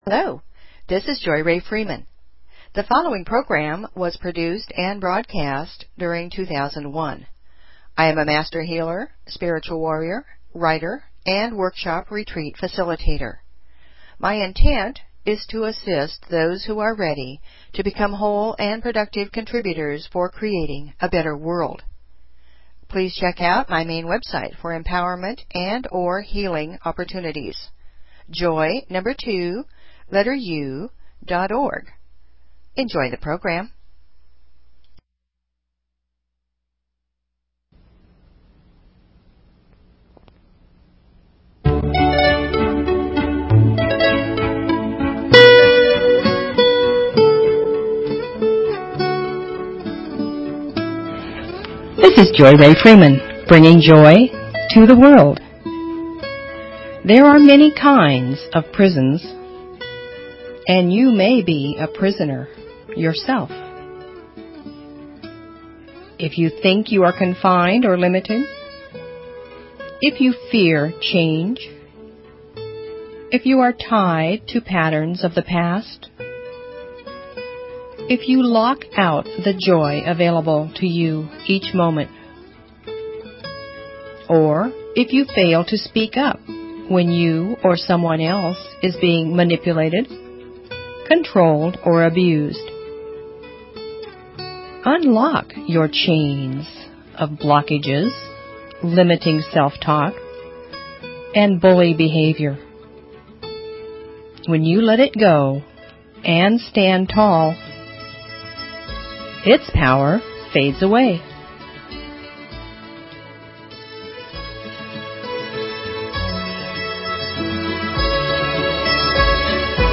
Talk Show Episode, Audio Podcast, Joy_To_The_World and Courtesy of BBS Radio on , show guests , about , categorized as
MANY KINDS OF PRISONS (2001) Music, poetry, affirmations, stories, inspiration . . .